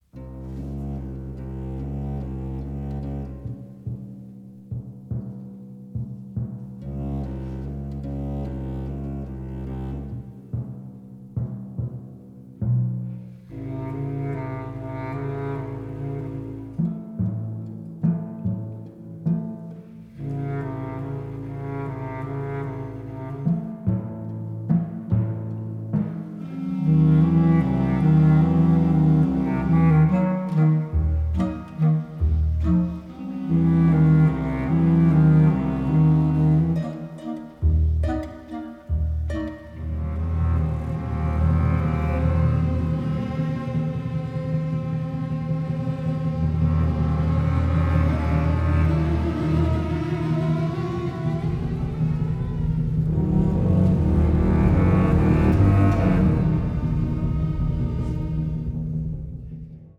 conducts a large orchestra recorded in Los Angeles.